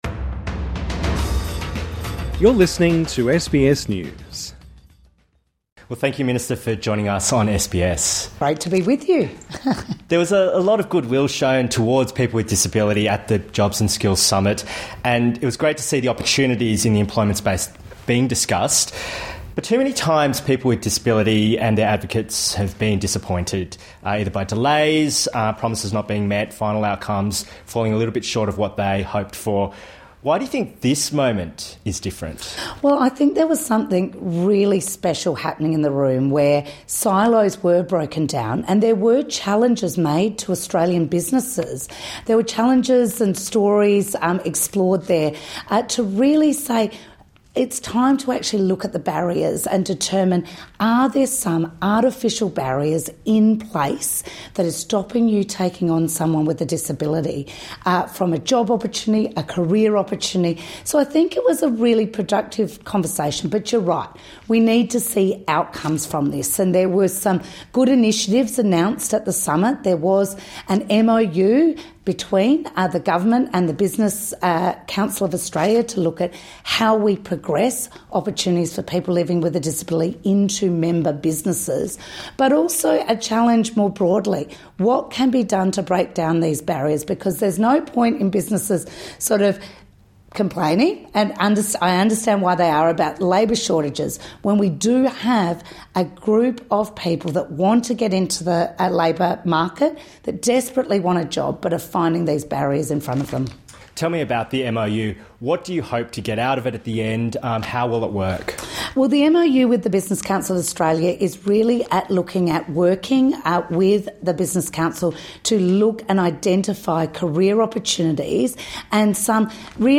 Minister for Social Services Amanda Rishworth speaks to SBS Source: SBS News / SBS